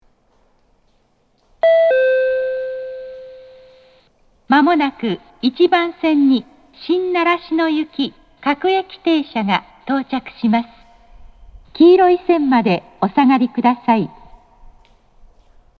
京葉型（女性）
接近放送